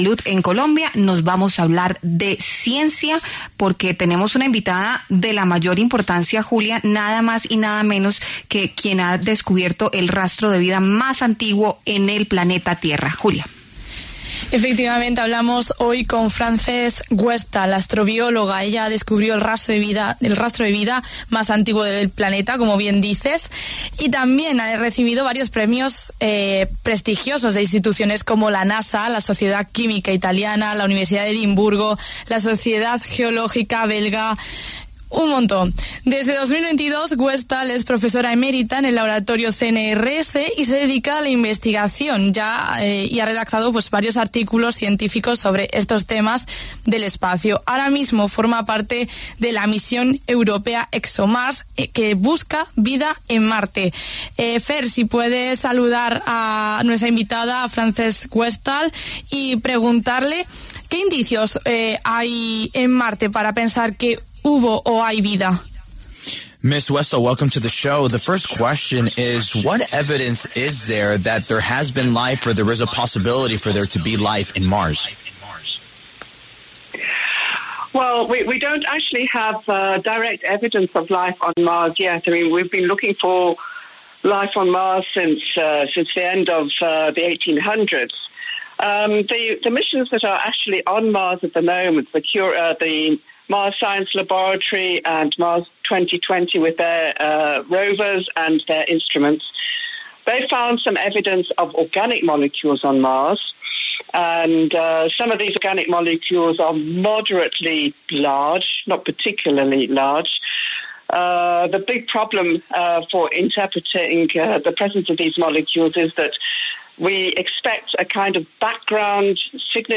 ¿Hay o hubo vida en Marte; estamos solos en el universo? Astrobióloga responde